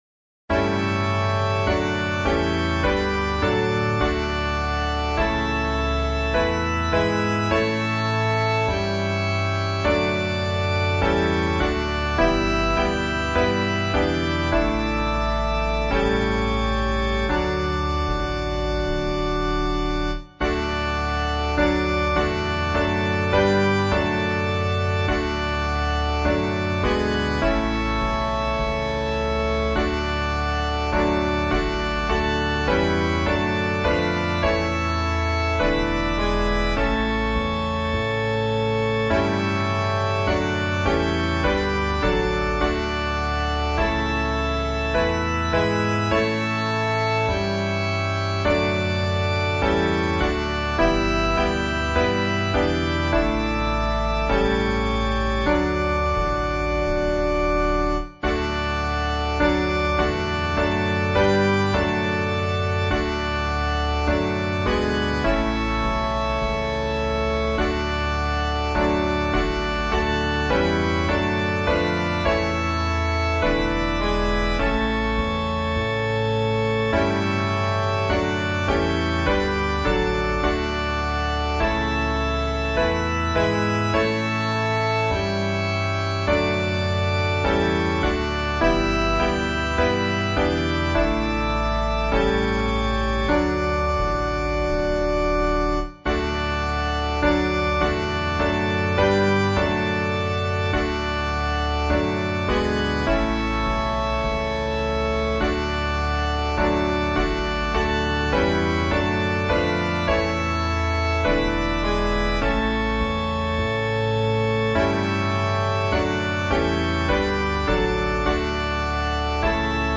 伴奏
原唱
其风格庄严，平稳，充满祈祷和敬畏的精神，富有艺术的感染力。